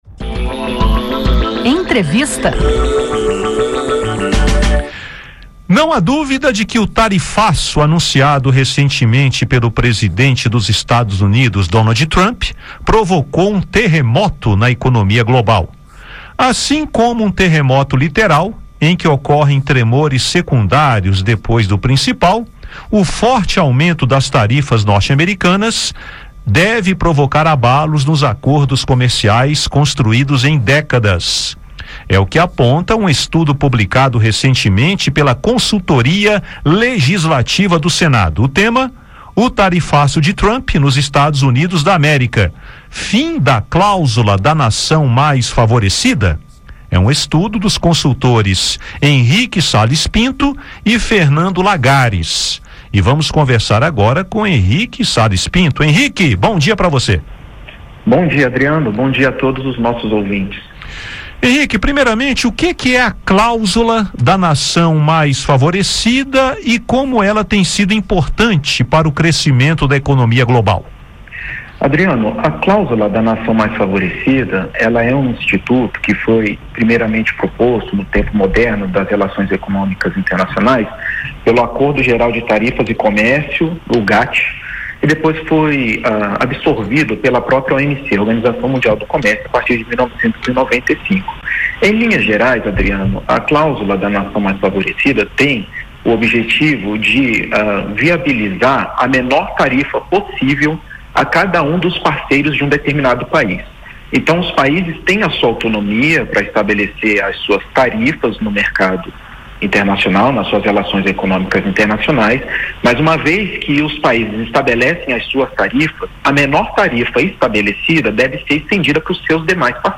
Em entrevista